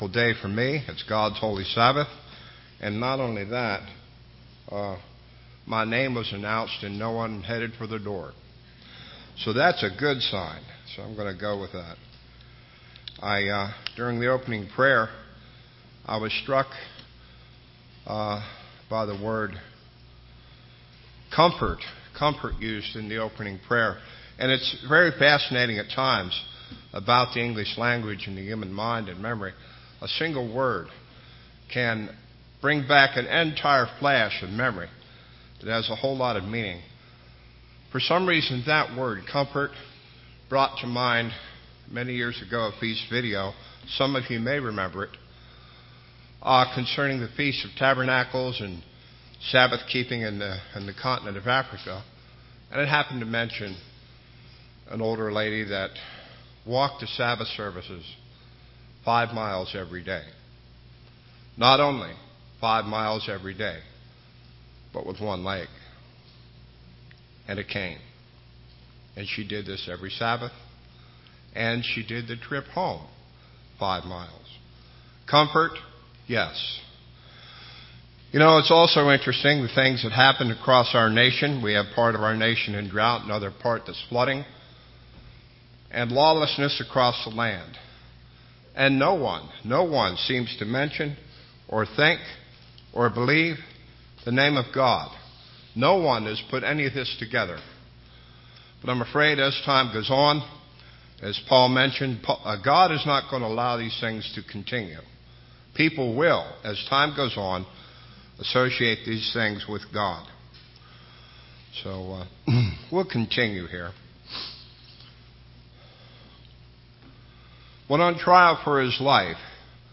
Sermons
Given in Rome, GA